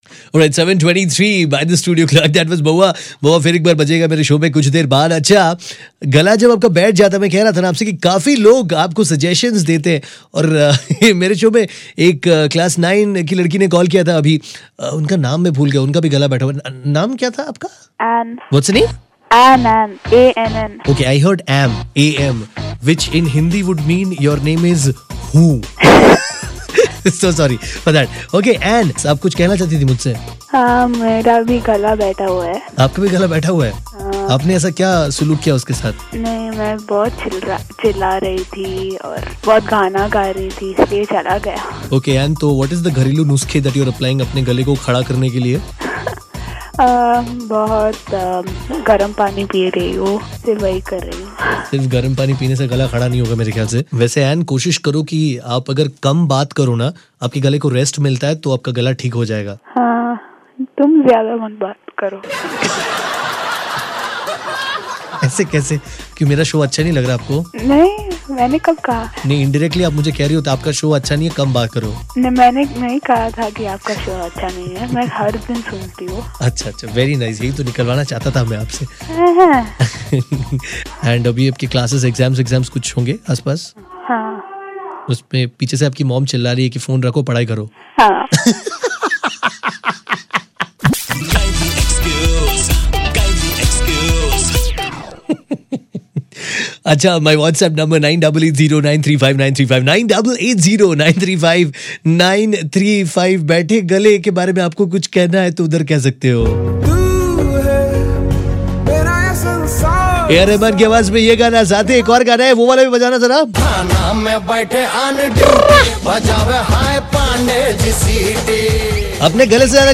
When a sore throat meets another one... this is how they sound